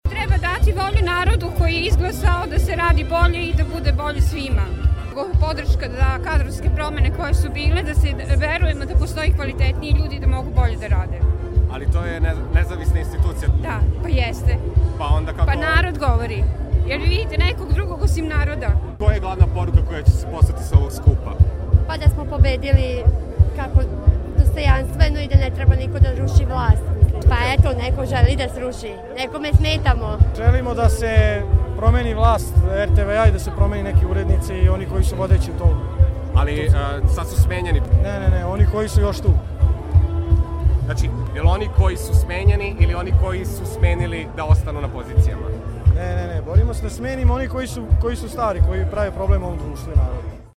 Novosađani o kontramitingu